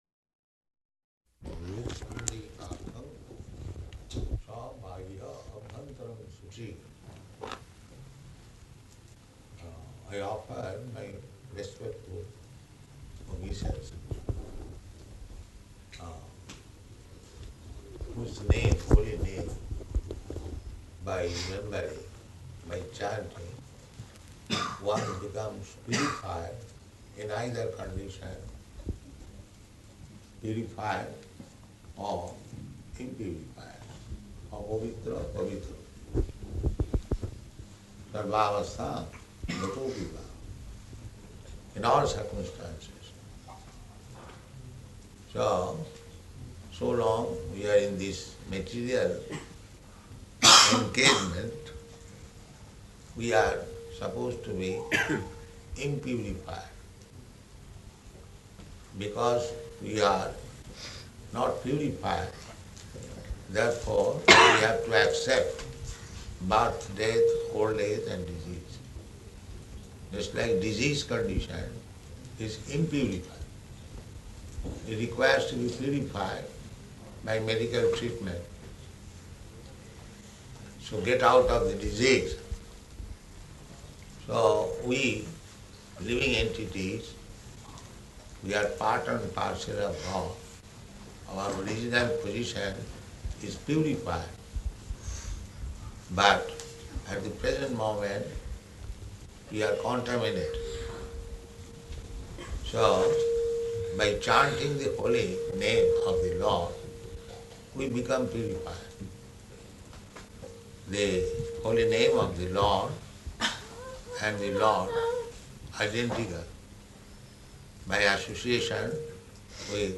Initiation Ceremony
Type: Initiation
Location: Melbourne